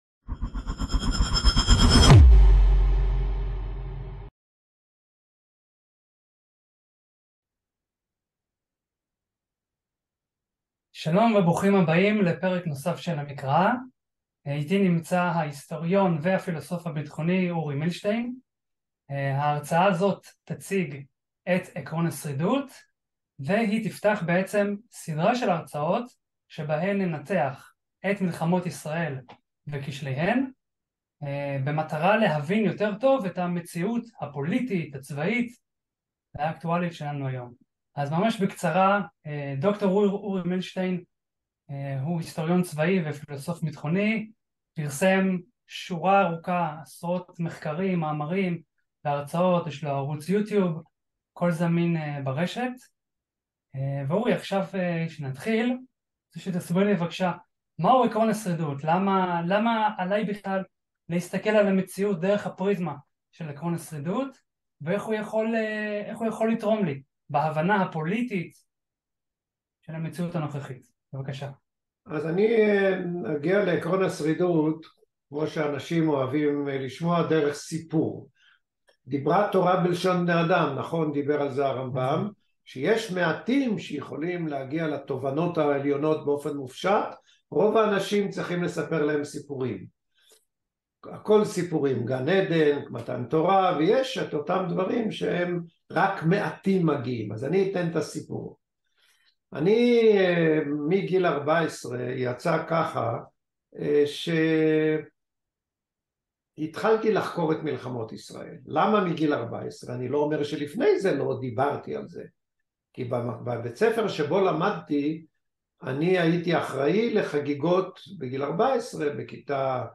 שיחת עומק ראשונה על מלחמות ישראל